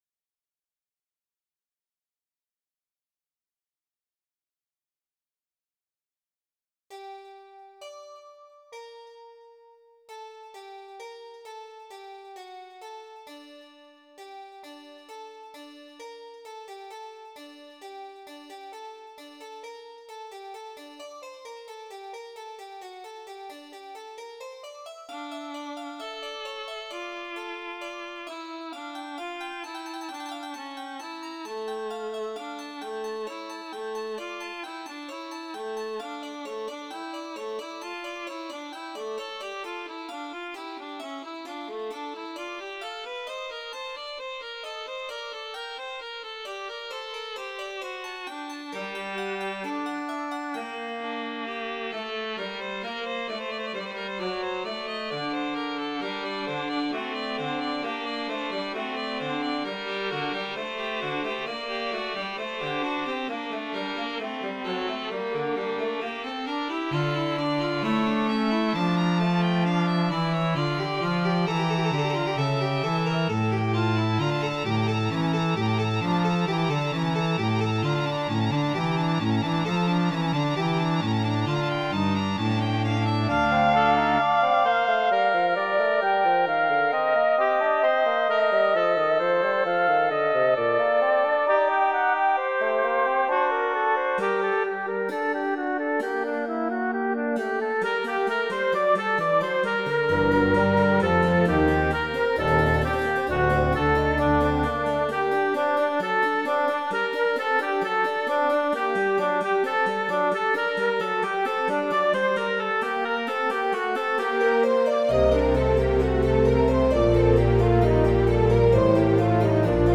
音源は全てVSC-88です。
それをオーケストラと弦楽カルテットにアレンジしました。